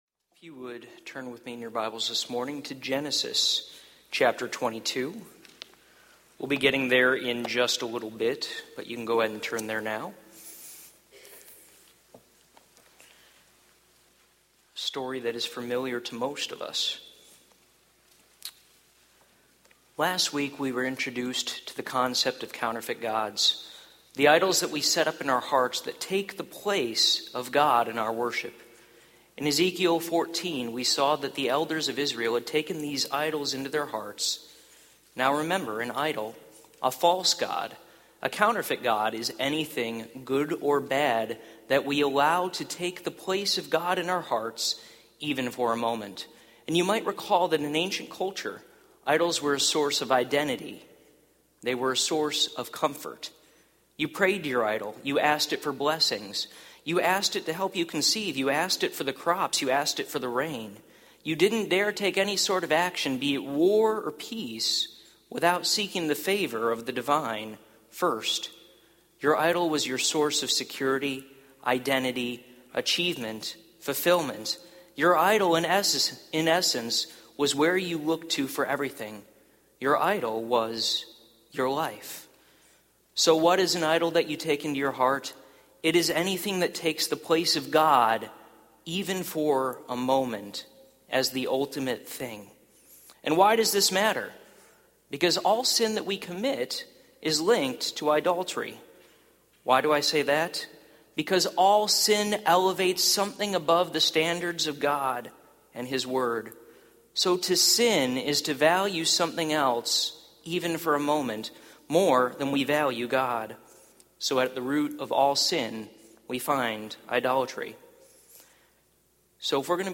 Topical Service Type: Sunday Morning « The Prodigal God